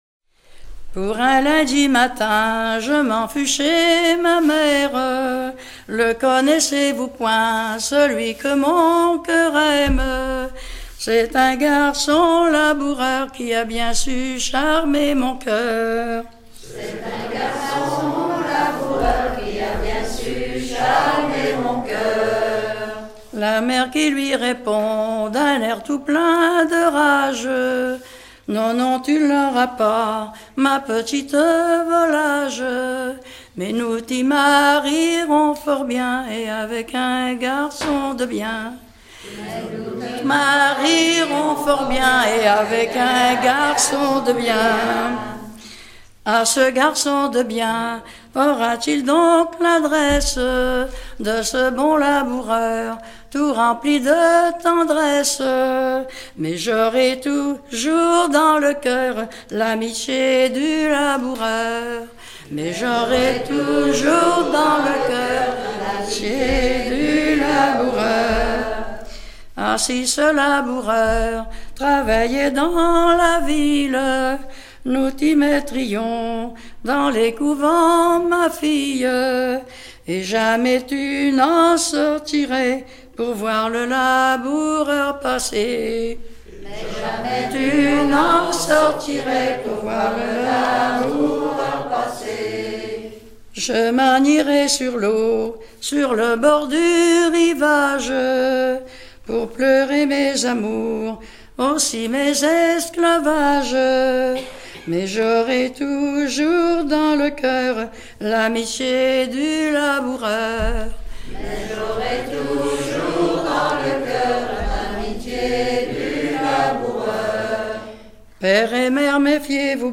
Localisation Falleron ( Plus d'informations sur Wikipedia ) Vendée
Genre strophique
Pièce musicale éditée